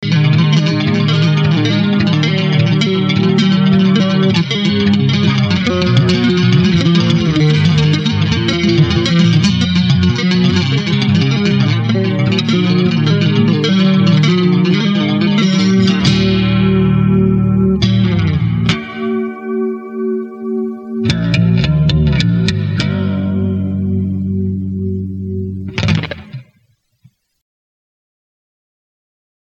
NOTE: bass solo